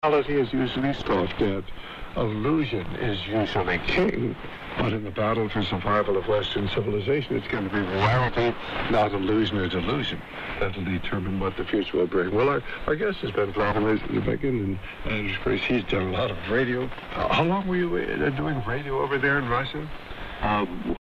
Sound bytes - Here are 3 audio snippets recorded on a Zoom H4 professional digital recorder of the same frequency over a period of a couple of minutes using the Eton E100, the Eton E1 and the Sony 2010:
The Eton E100 filter sounds, to my ear, about 2.2khz or so - The Eton E1 (in all fairness) was set at 7khz w/ Sync lock on so it sounded a tad strident.